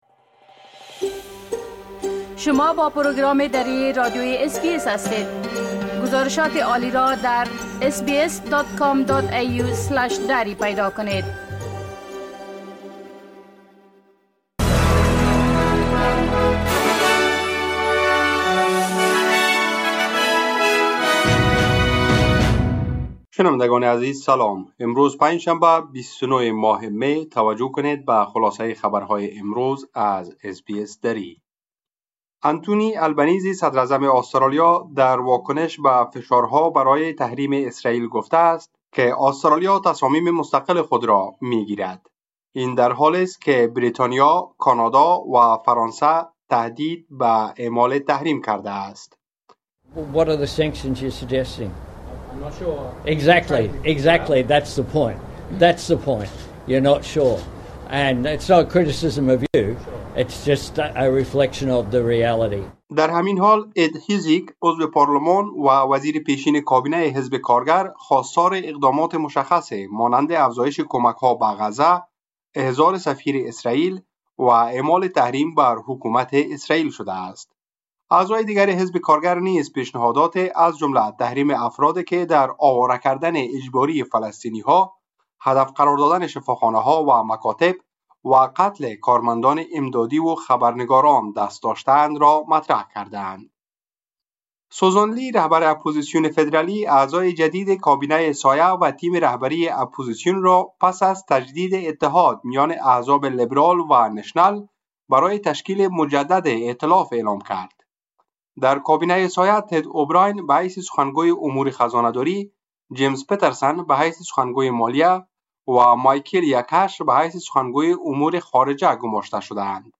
خلاصه مهمترين اخبار روز از بخش درى راديوى اس‌بى‌اس